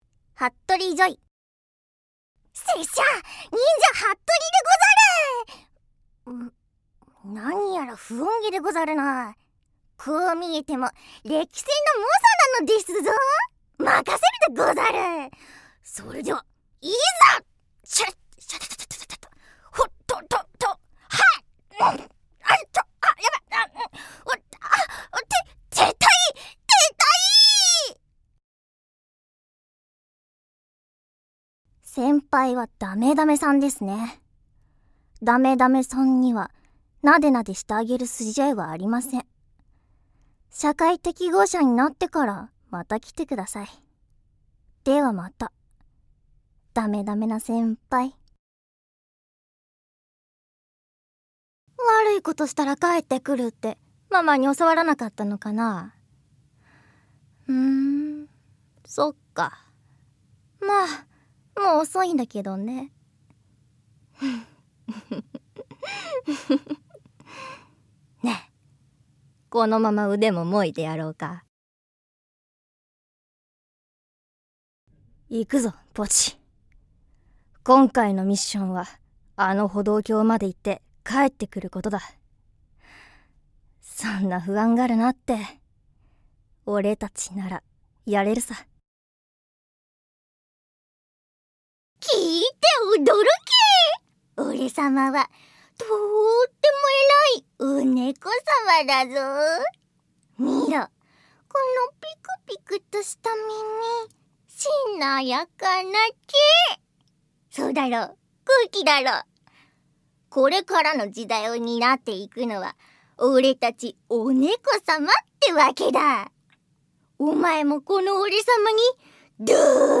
◆ボイスサンプル◆